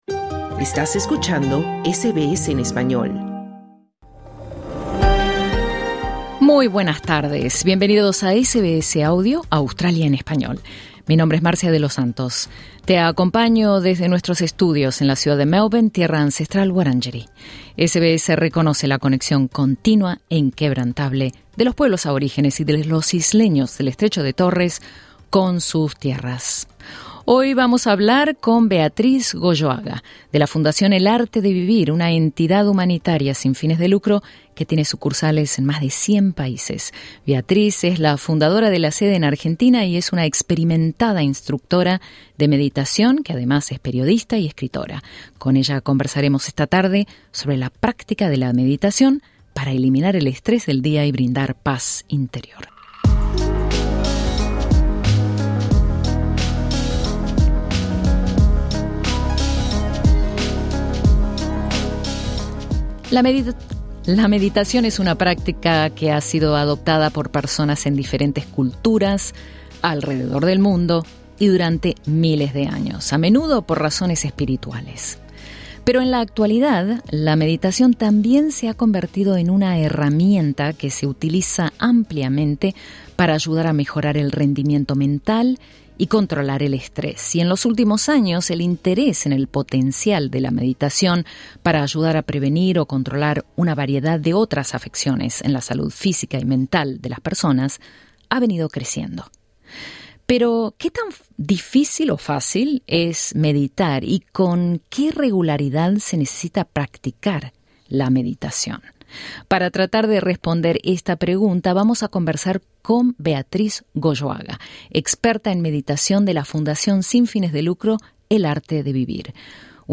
conversa con SBS Spanish en los estudios de SBS Melbourne.